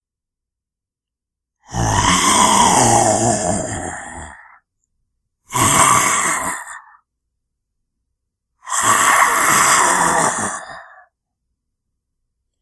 Freesound三部曲僵尸咆哮
描述：这个剪辑包含三个类似的声音僵尸咆哮/咆哮。
标签： 生物 食尸鬼 呻吟 喘息 亡灵 喘气 邪恶 野兽 咆哮 令人毛骨悚然 僵尸 死了 咆哮 恐怖 怒吼 可怕 呻吟声 怪物的咆哮 咆哮
声道立体声